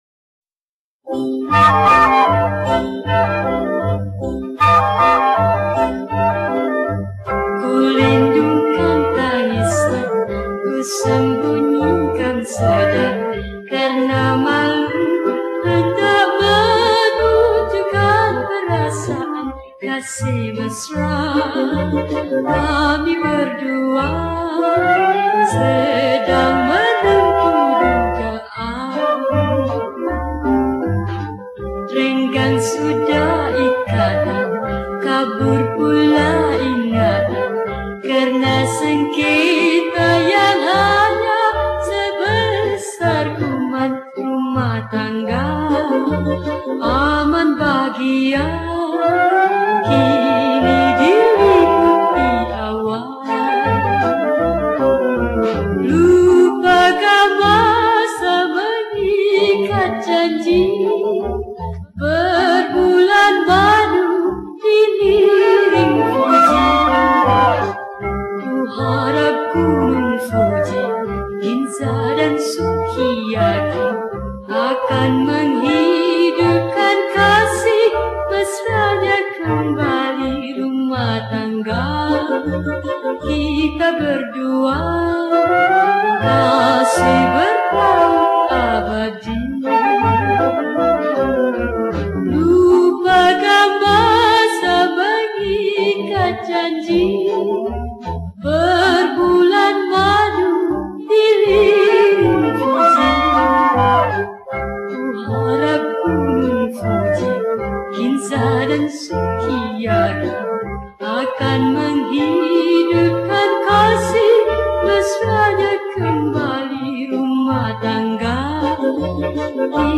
Adapted From : Japanese Evergreen Song